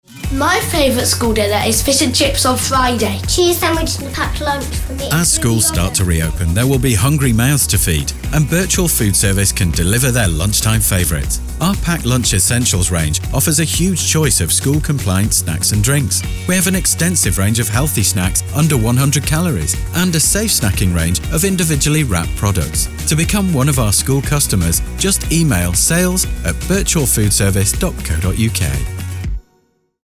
Last month, we launched our first ever radio campaign!